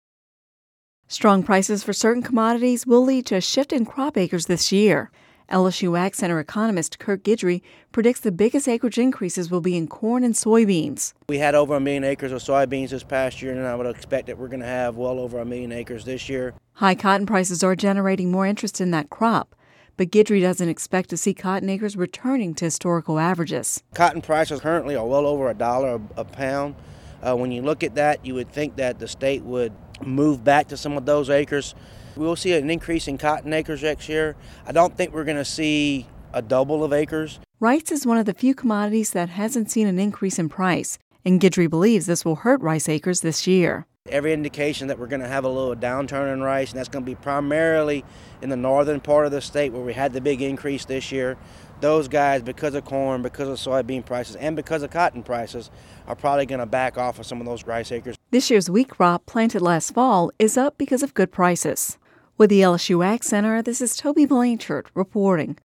(Radio News 01/17/11) Strong prices for certain commodities will lead to a shift in crop acreage this year.